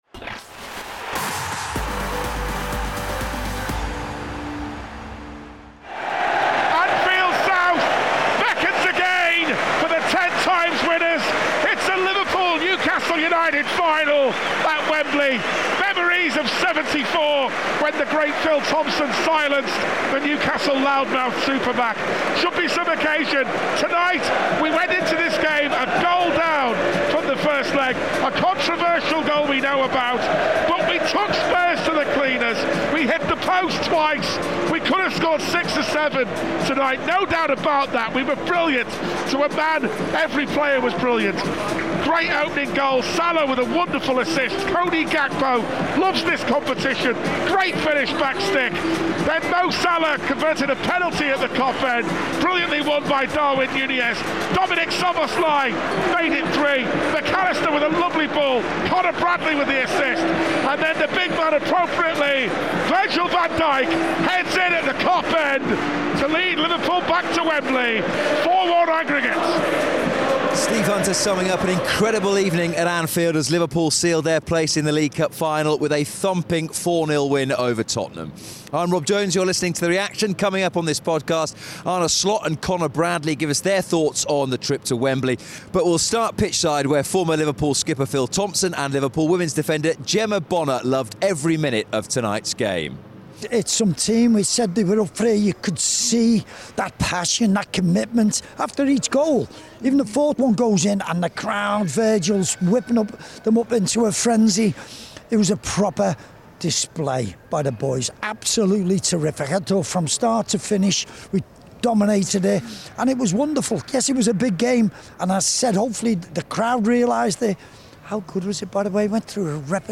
Arne Slot and Conor Bradley react to the 4-0 thrashing of Tottenham in the second leg of the Carabao Cup semi-final, giving Liverpool a 4-1 aggregate win which sends the Reds to Wembley for a second successive season.